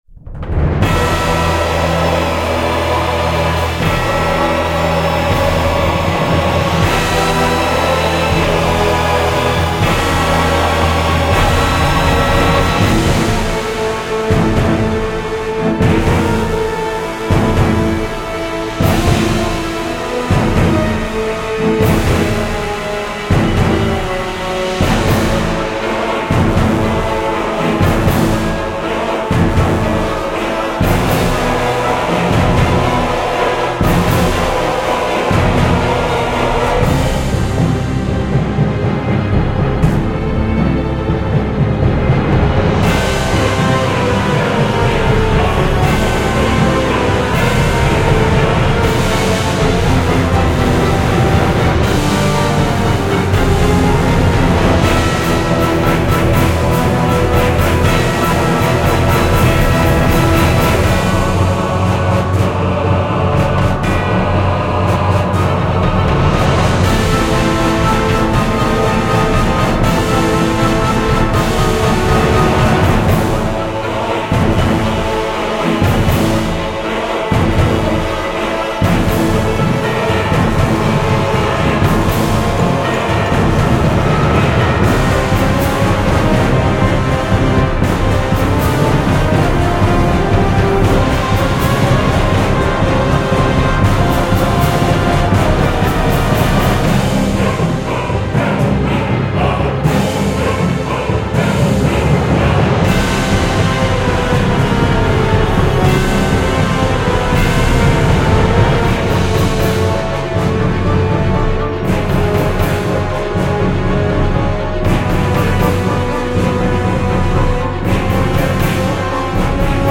Жанр: Score
Исполнение исключительно инструментальное.